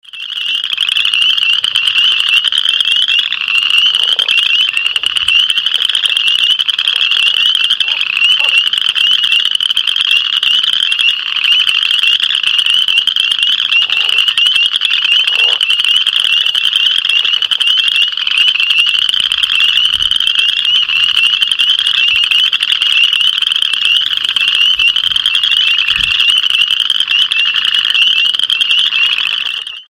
Boreal Chorus Frog (Pseudacris maculata)